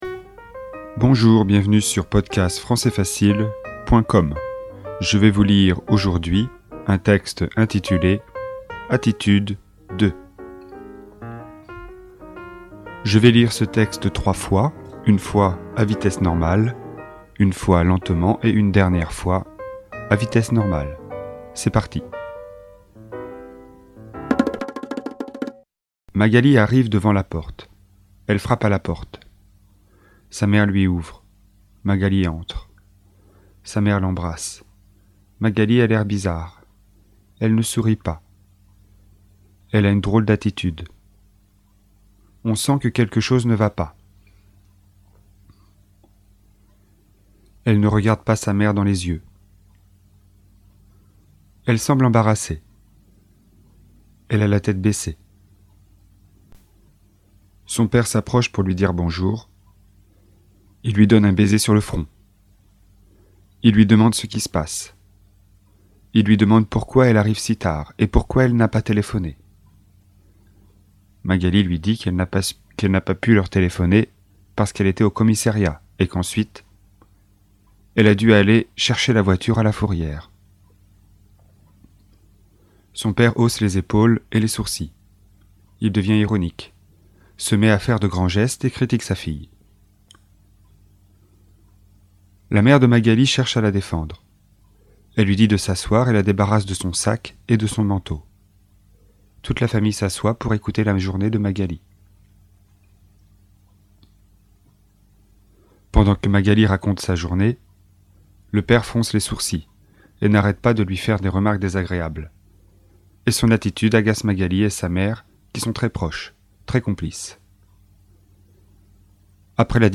Texte FLE, niveau débutant (A1), sur le thème des attitudes.